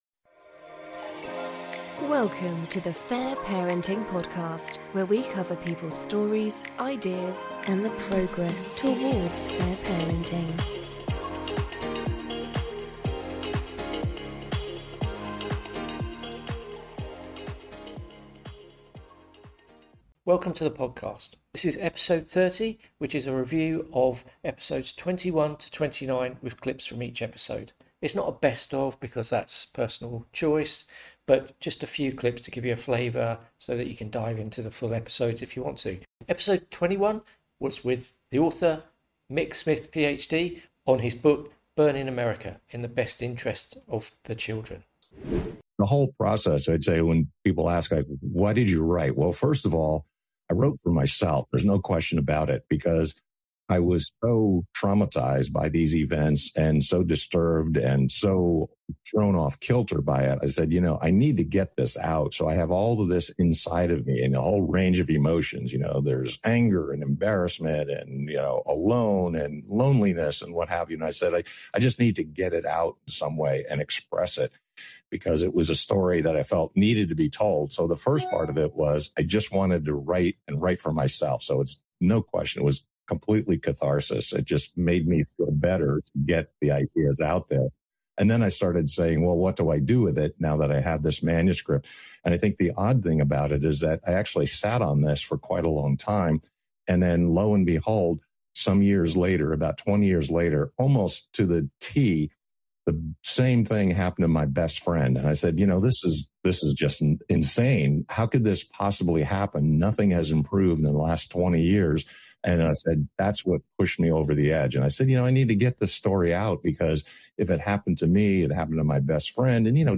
This episode is a compilation of episode clips rather than a ‘best of’ clips as that is individual choice. It is for regular listeners and also a taster for people new to the podcast.